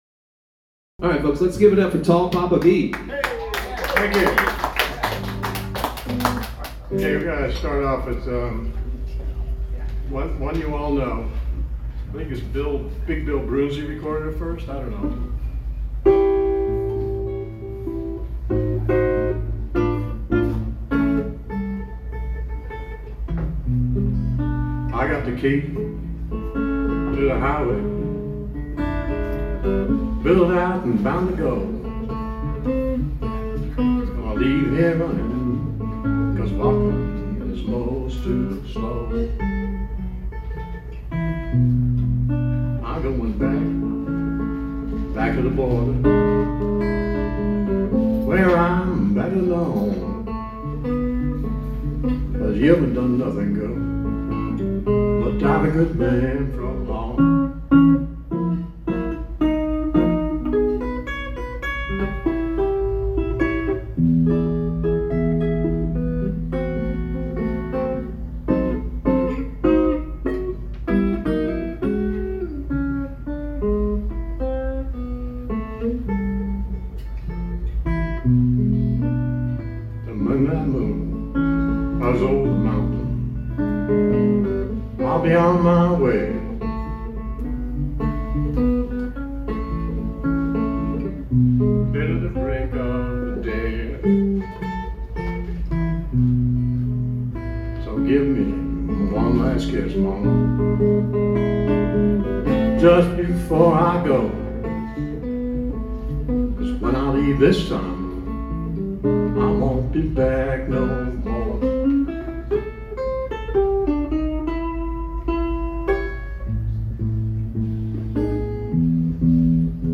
a list of songs for Open Mic
8 bar blues in A
jug band or blues
Guiild guitar in standard tuning.